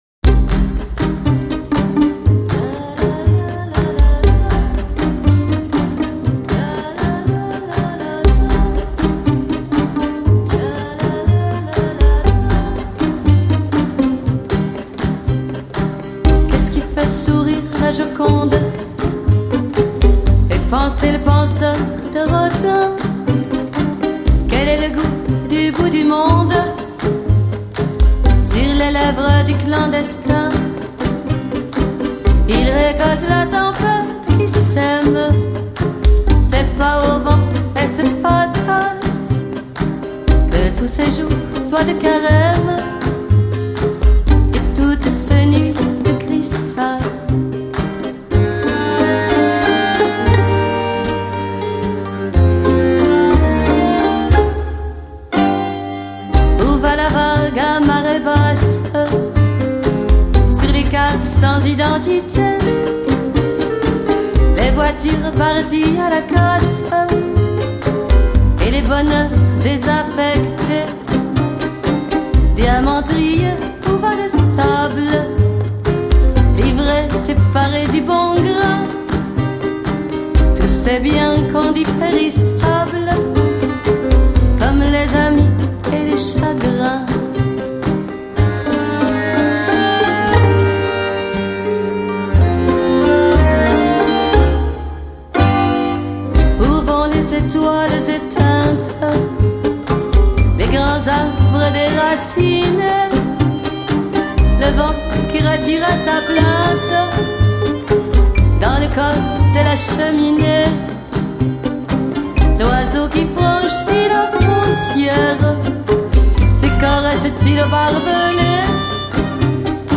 演唱歌手：新世纪音乐